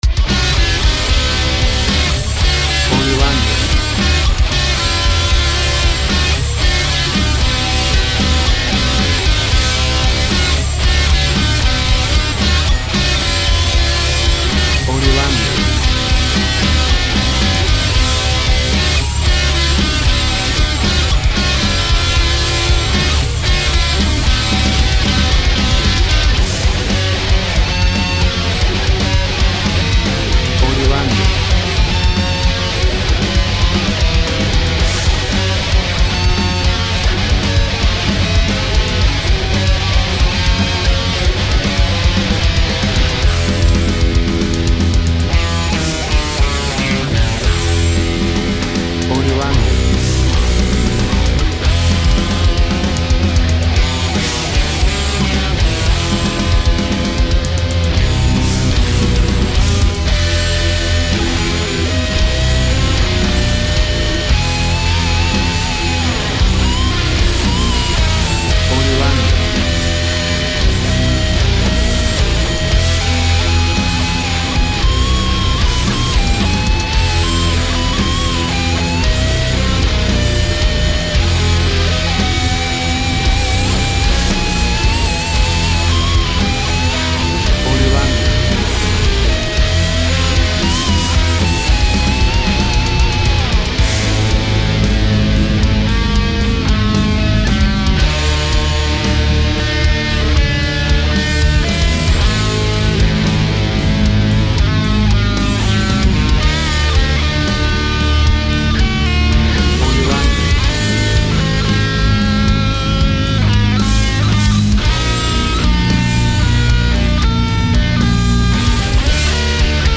Heavy Rock sounds.
Tempo (BPM) 120